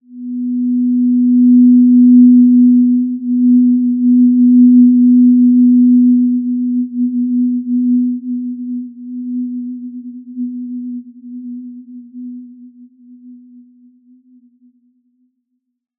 Basic-Tone-B3-mf.wav